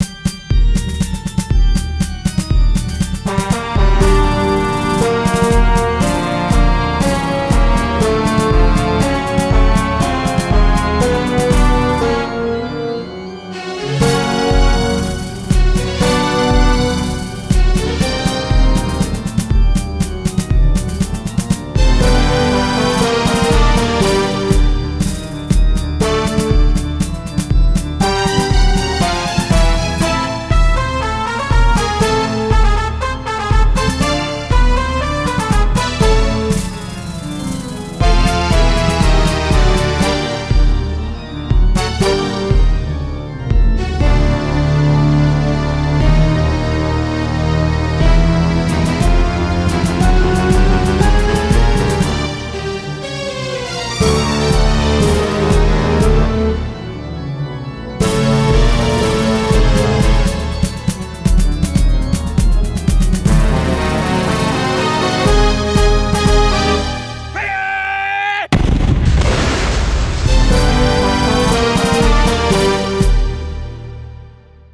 Resumo: Trilha sonora criada para a fase "Pirata"